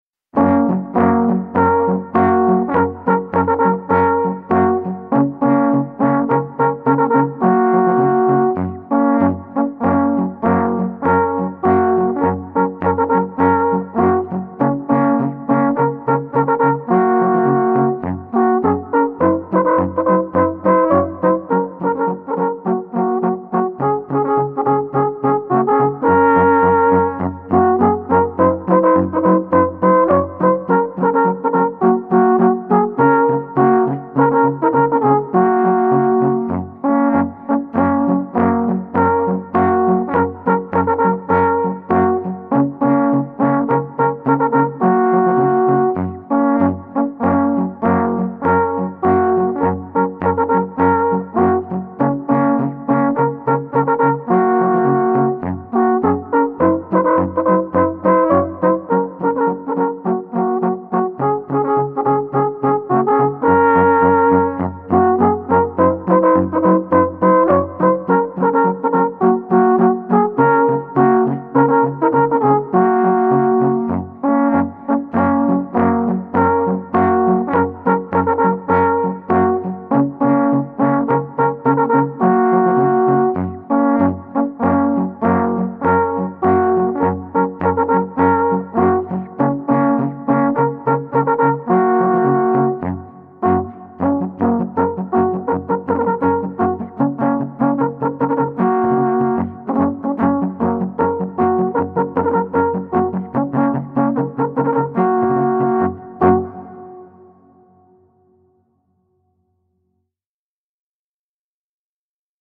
Instrumental / Alphorn.